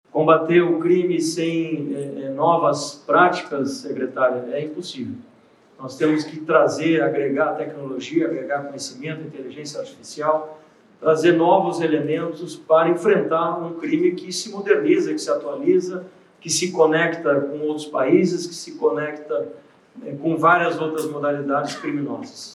Fala do diretor-geral da PF, Andrei Rodrigues, no Encontro Nacional dos Usuários da RedeMAIS.mp3 — Ministério da Justiça e Segurança Pública